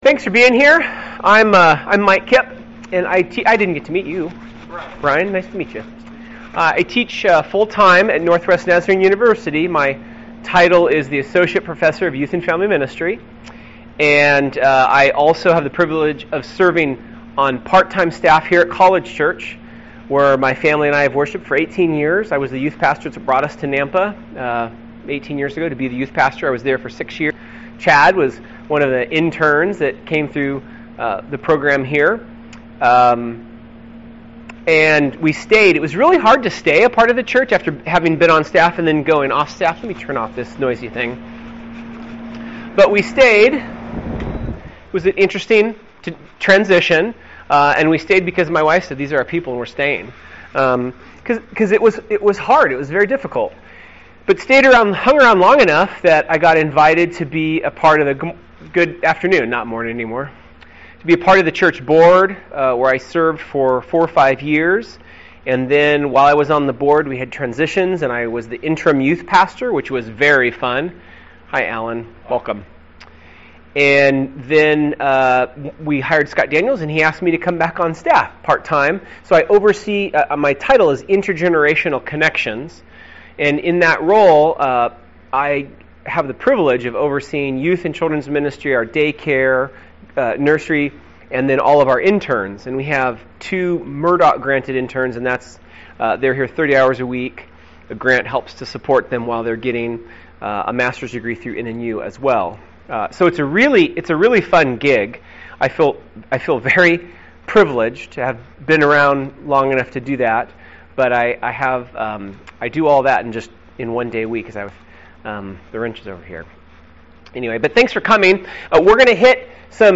This interactive seminar will present two foundational biblical anchors for the practice of intergenerational ministry. Recent research will be shared indicating the critical importance of engaging parents, youth, and the entire church body in preparing young people for faithful lives as followers of Jesus Christ.